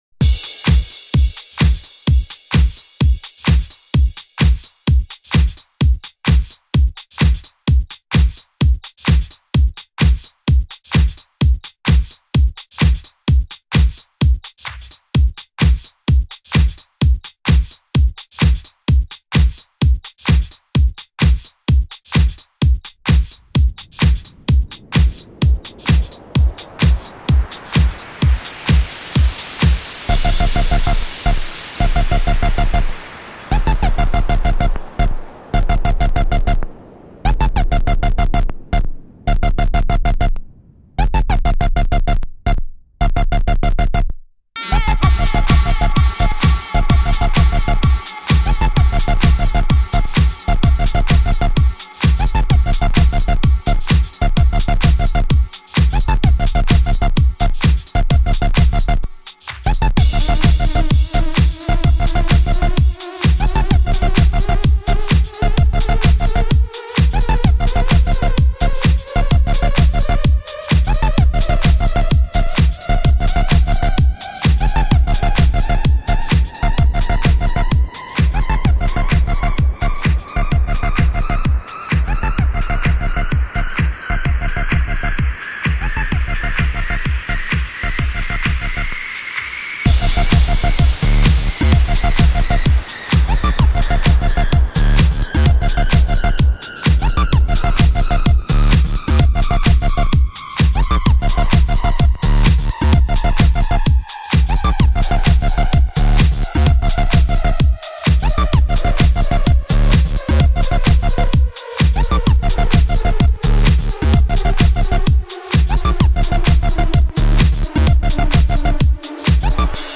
So for now, have this electro thingy!